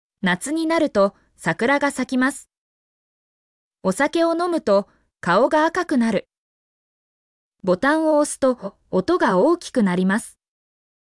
mp3-output-ttsfreedotcom-47_DoTwYdHo.mp3